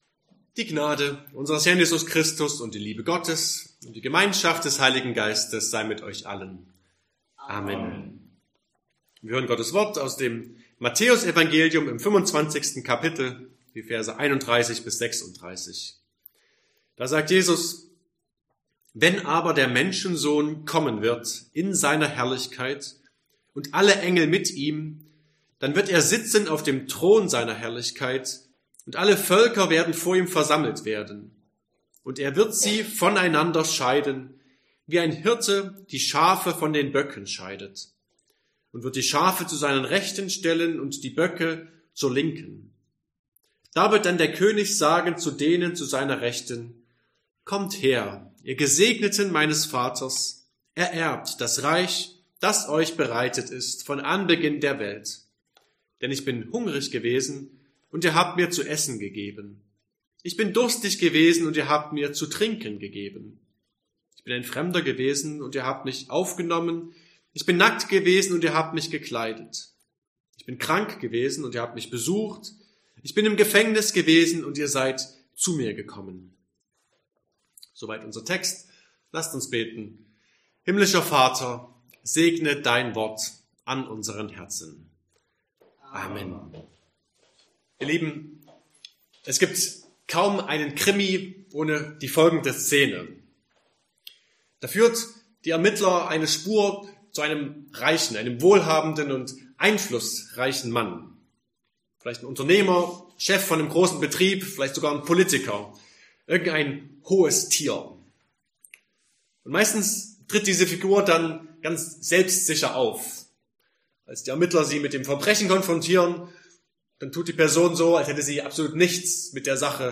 Drittletzter Sonntag im Kirchenjahr Passage: Matthäus 25, 31-36 Verkündigungsart: Predigt « 23.